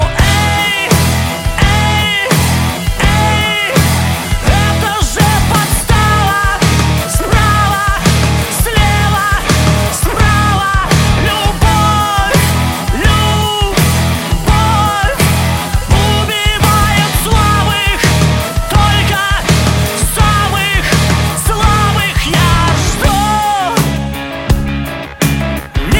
рок , громкие
альтернатива